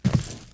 FootstepHeavy_Concrete 06.wav